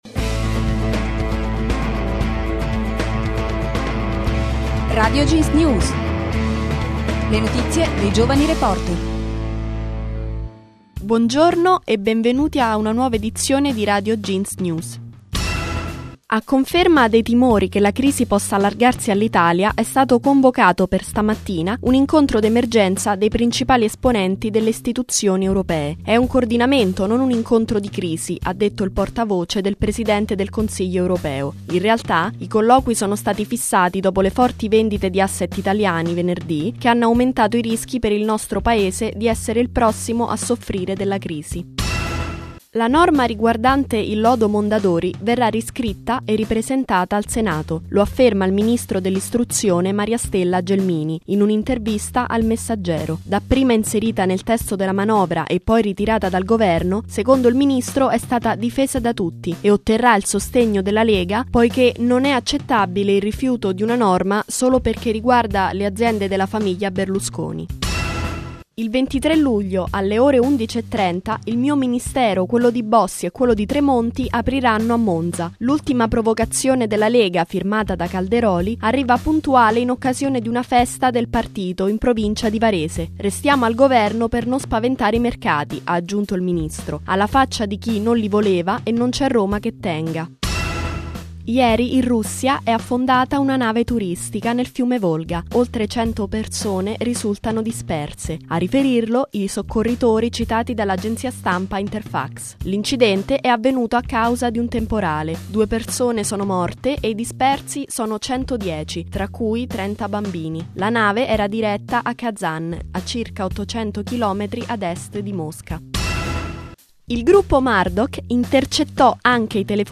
Le notizie dei giovani reporter